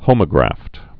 (hōmə-grăft, hŏmə-)